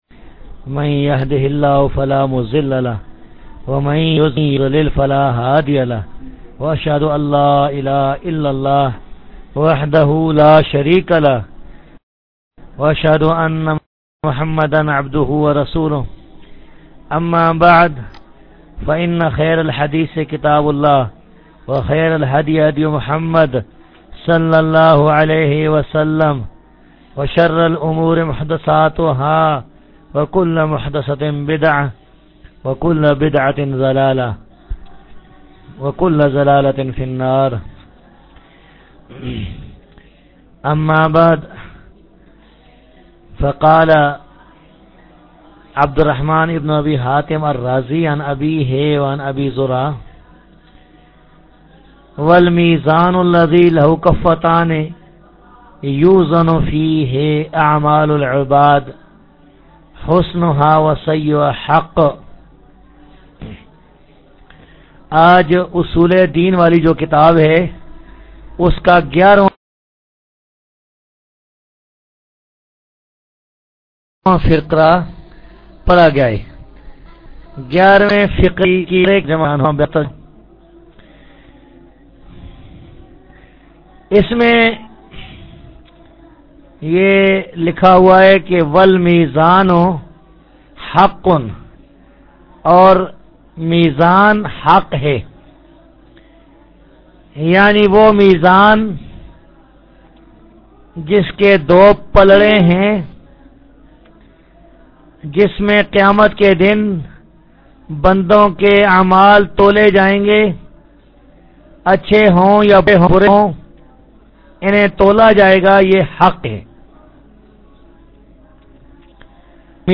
Dars-e-Hadeeth Aur Sawaal Jawaab 26-9-04